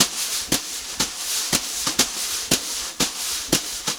120JZBEAT1-L.wav